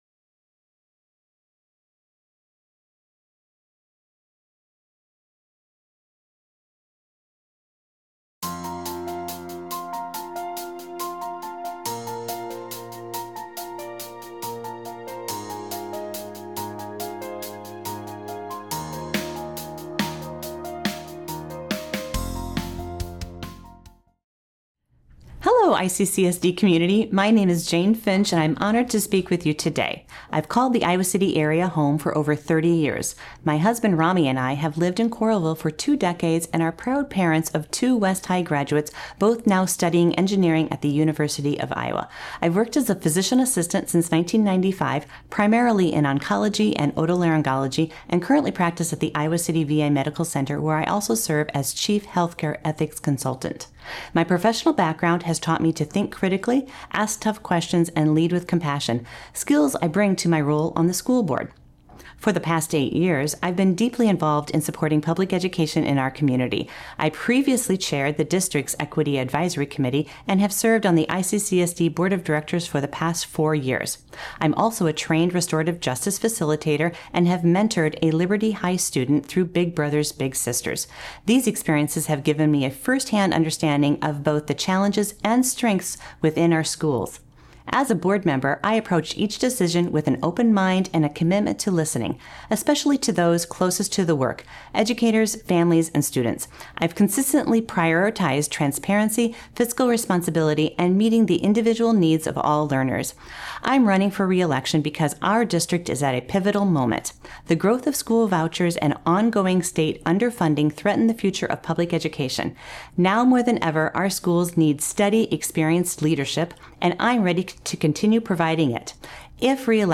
City Channel 4's Meet the Candidates project invited all of the registered candidates for the November 4th Iowa City Community School District Board of Directors election to come to our studio to present themselves to voters in five minutes or less.